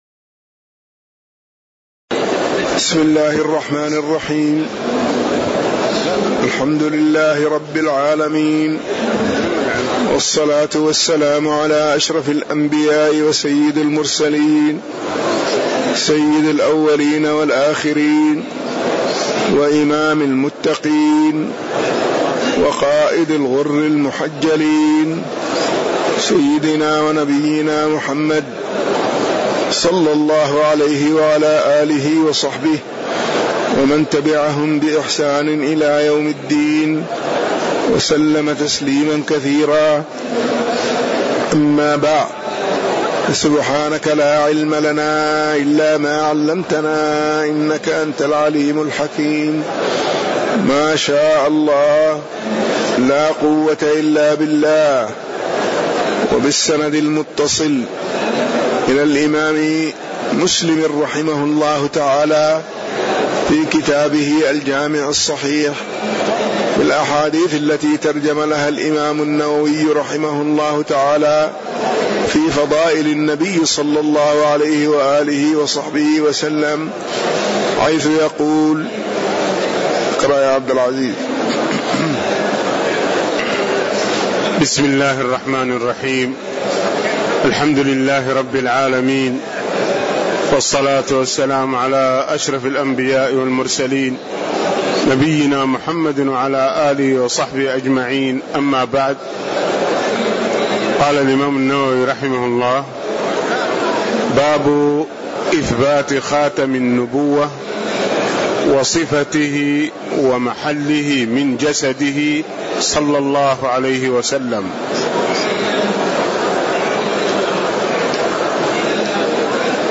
تاريخ النشر ٥ جمادى الآخرة ١٤٣٧ هـ المكان: المسجد النبوي الشيخ